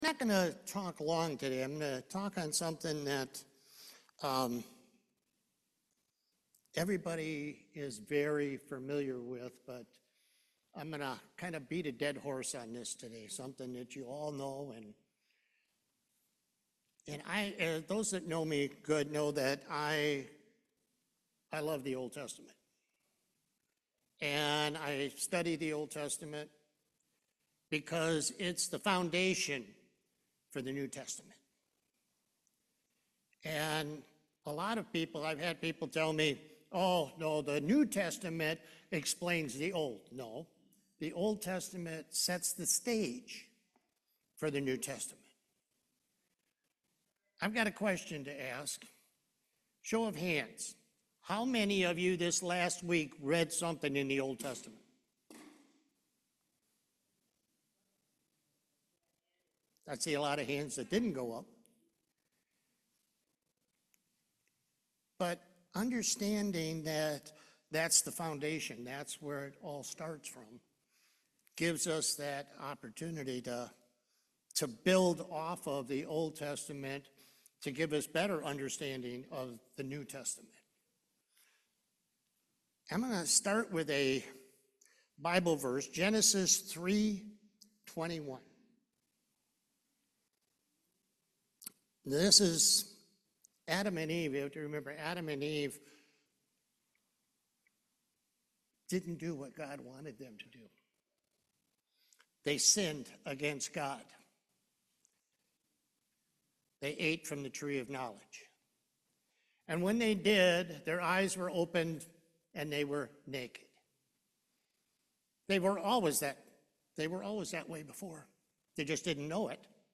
Exodus 12 Service Type: Main Service The Old Testament is the foundation for the New Testament.